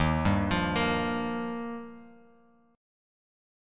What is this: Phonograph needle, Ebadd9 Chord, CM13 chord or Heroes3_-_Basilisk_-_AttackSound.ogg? Ebadd9 Chord